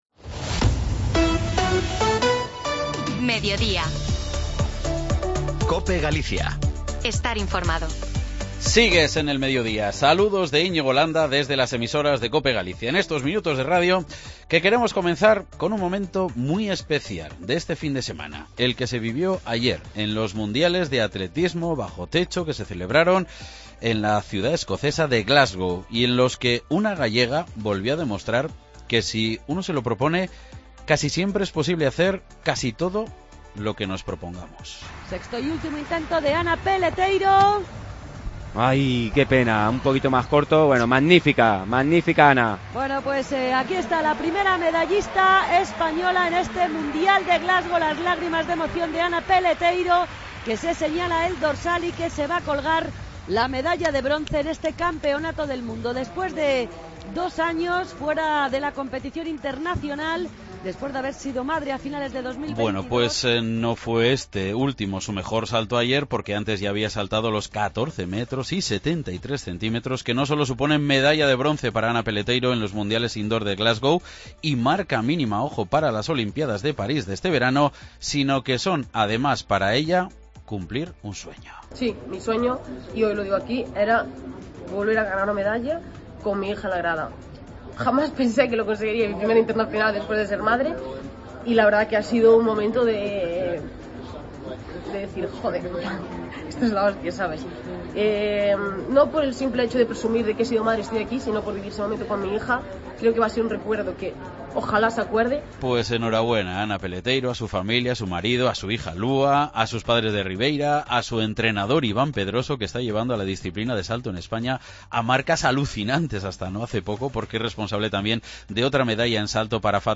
AUDIO: Recuperamos los sonidos de la medalla de bronce de la atleta gallega Ana Peleteiro en la prueba de triple salto de los Mundiales de Glasgow...